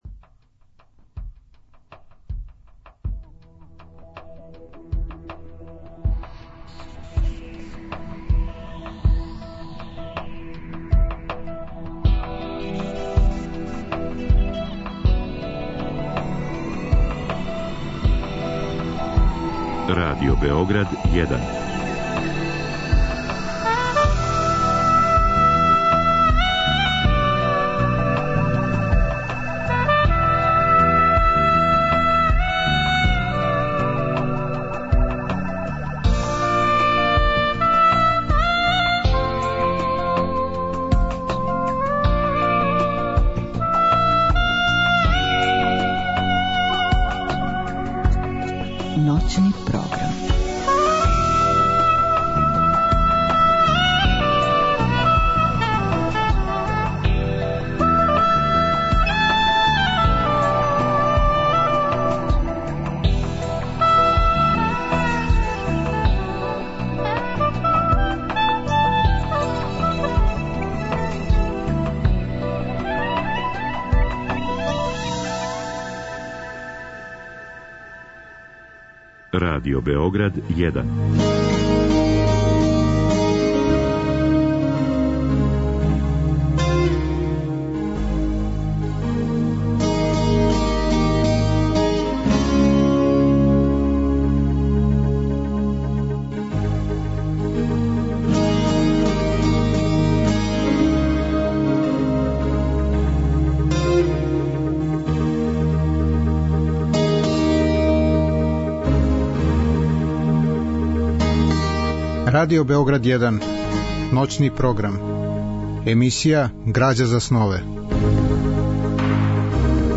Разговор и добра музика требало би да кроз ову емисију и сами постану грађа за снове.
Чућемо и изјаве самог Нацукија Икезаве забележене у октобру ове године приликом његове посете Србији.